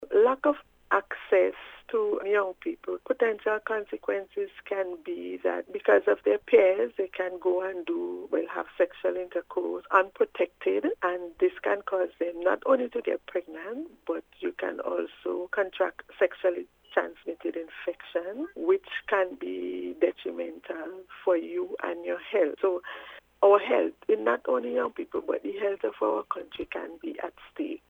In an interview with NBC News